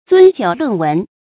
樽酒論文 注音： ㄗㄨㄣ ㄐㄧㄨˇ ㄌㄨㄣˋ ㄨㄣˊ 讀音讀法： 意思解釋： 唐杜甫《春日憶李白》詩：「何時一樽酒，重與細論文。」后遂以「樽酒論文」謂一邊喝酒，一邊議論文章。